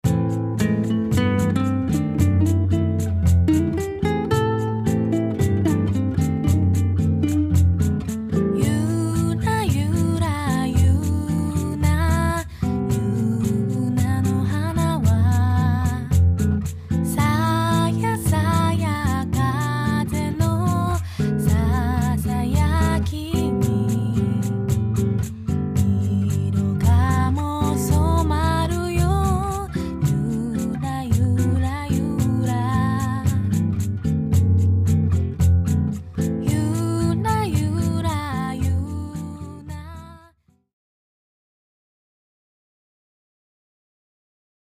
みんなの大好きな沖縄の名曲たちをボサノバで歌いました！